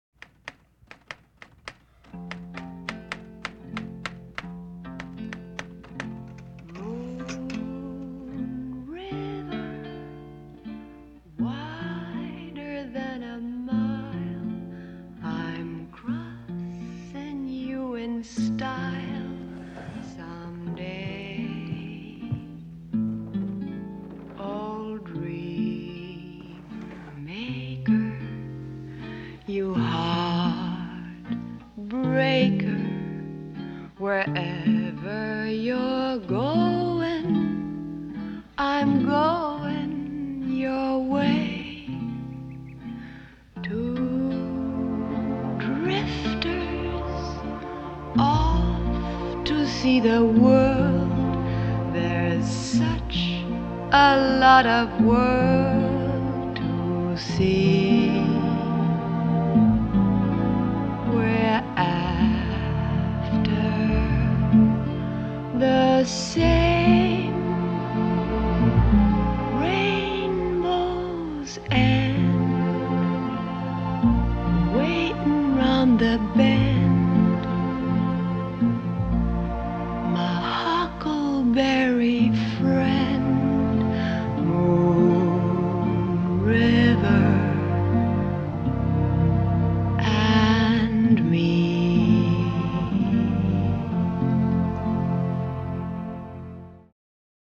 感觉蓝光版的音质要好一些，背景白噪声小多了。